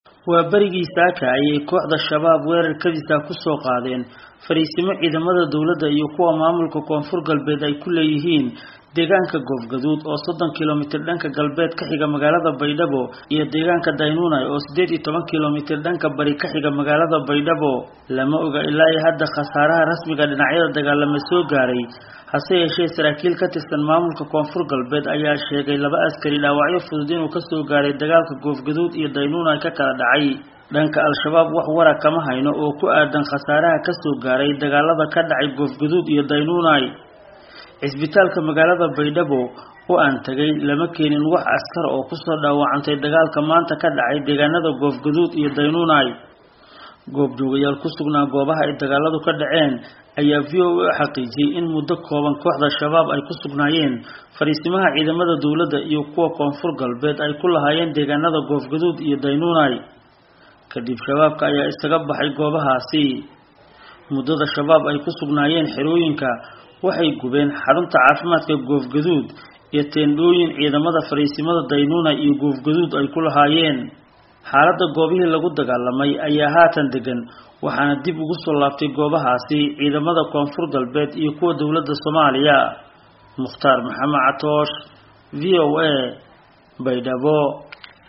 Warbixin ku saabsan weerarkaas